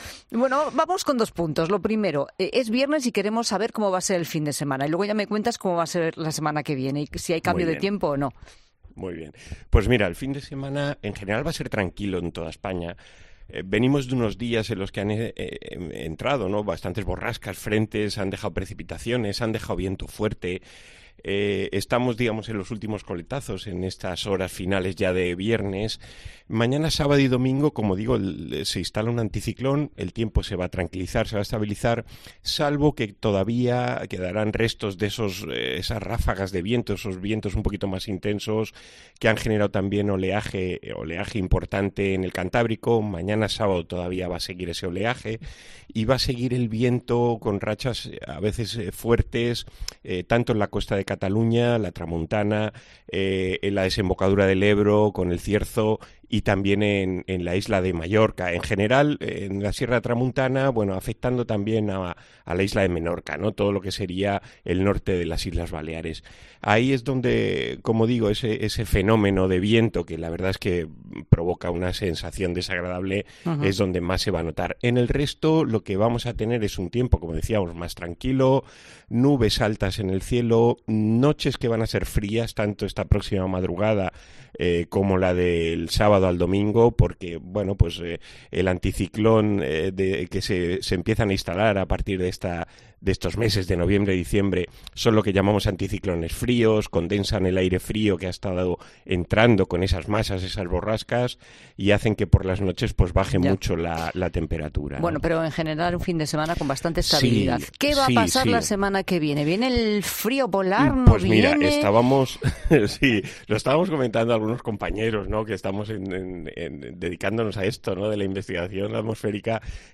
Un meteorólogo predice en COPE el tiempo que hará este mes de diciembre: a largo plazo